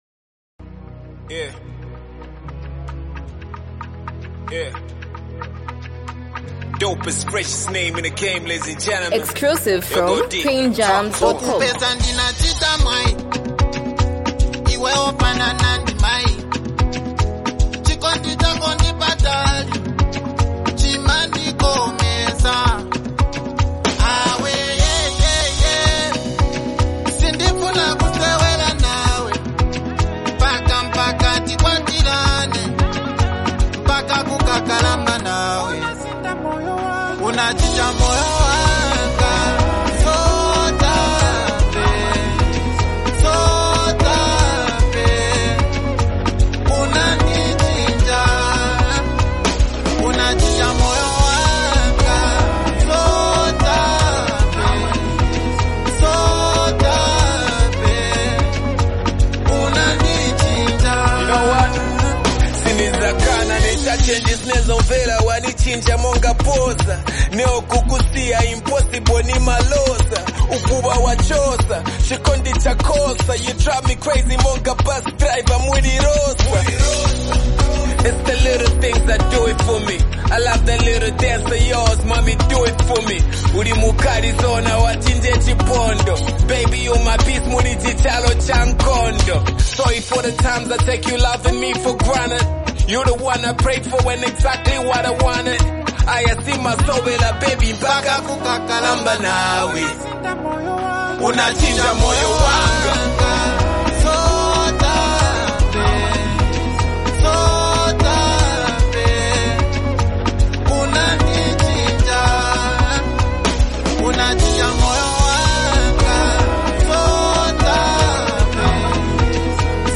hit banger